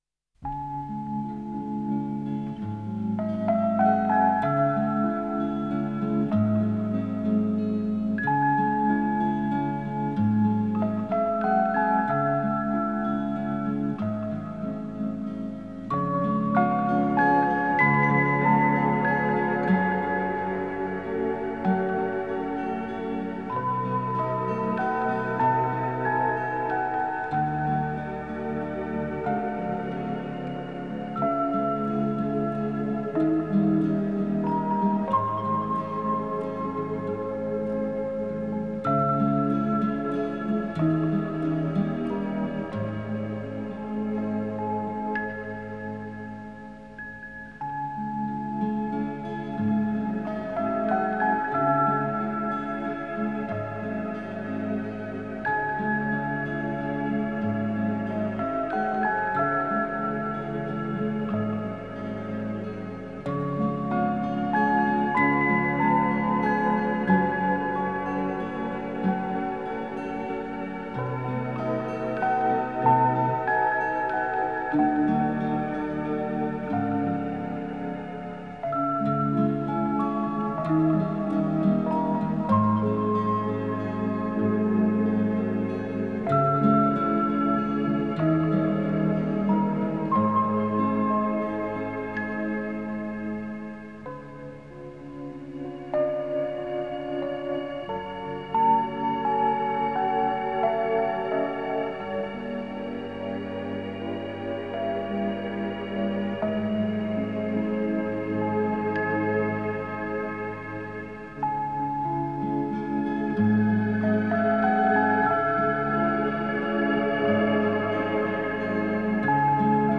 красиво....і мелодія така ніжна give_rose 16
Нежная...и стихи душевные.... 16